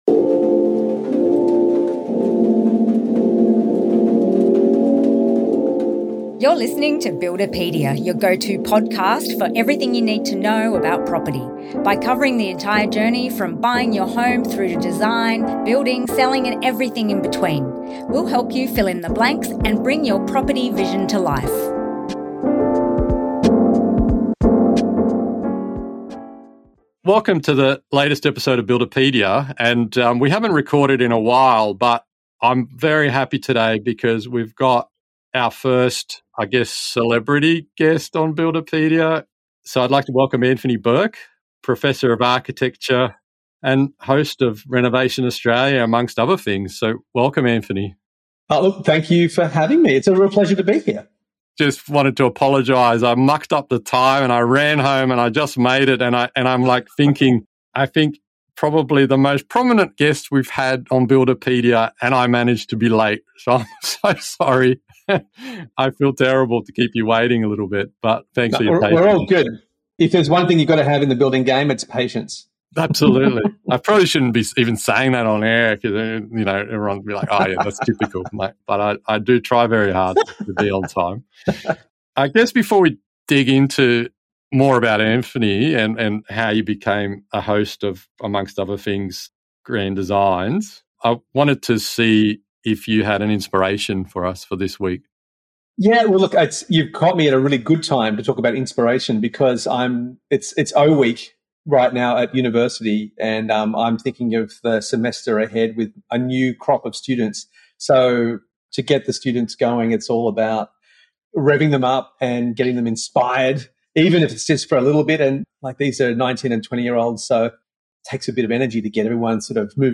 #26 - Grand Designs and the Future of Architecture: a discussion with Anthony Burke, host of Grand Designs Australia and co-host of Grand Designs Transformations and Restoration Australia | Buildipedia